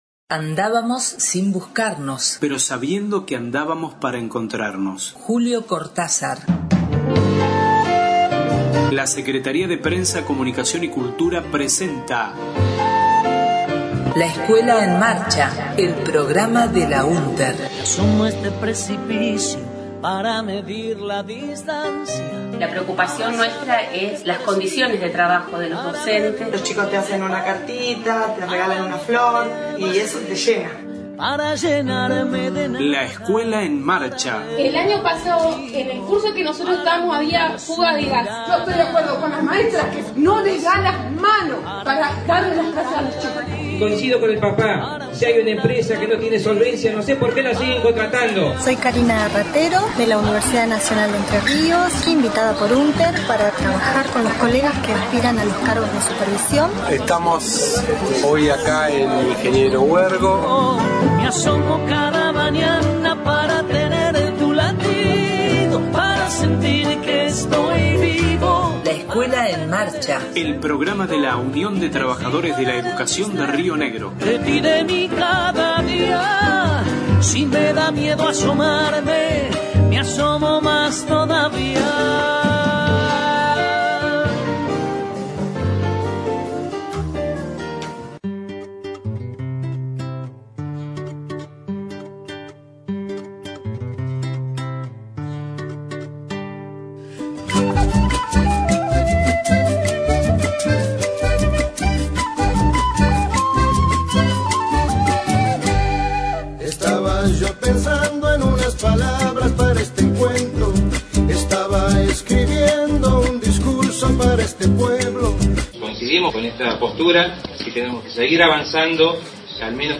en el XXXIII Congreso de CTERA realizado en Buenos Aries, el 13/08/15